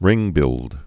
(rĭngbĭld)